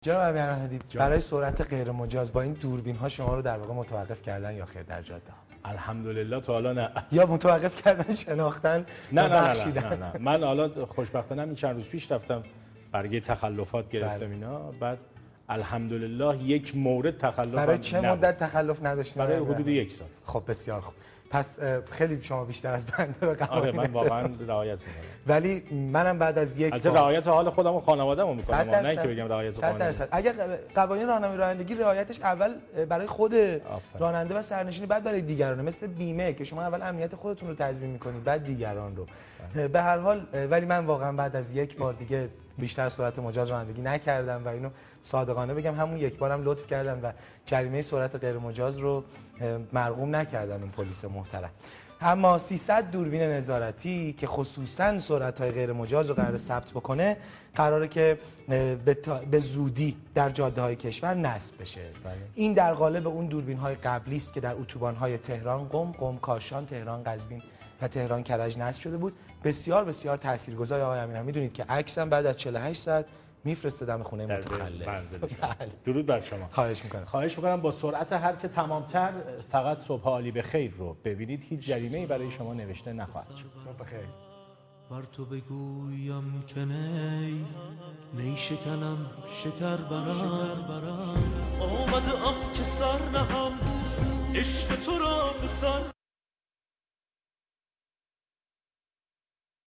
17. Dictation: Listen to this
(Courtesy: IRIB 1, 22-2-88, Sobh-e `Āli Bekhayr)